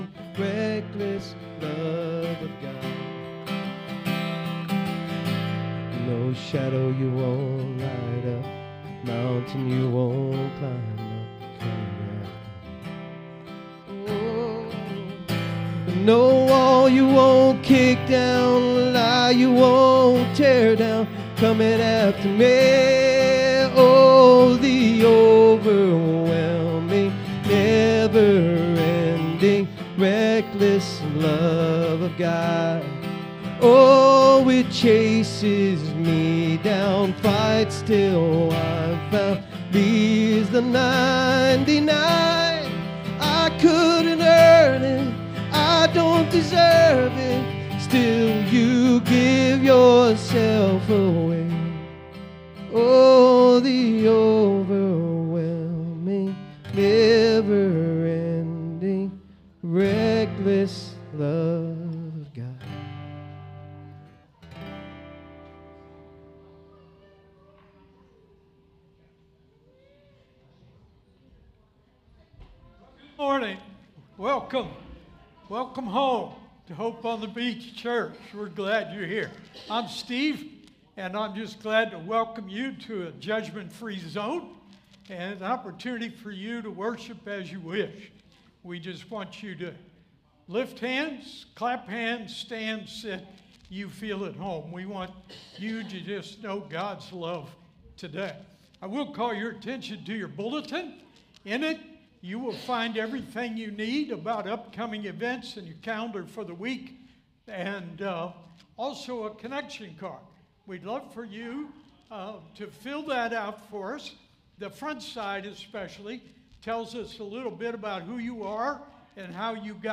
This sermon reminds us that God’s grace works through imperfect families and human weakness to fulfill His covenant purposes. Through Joseph’s divine dreams, we learn that God reveals His will and assures His people even when others may misunderstand.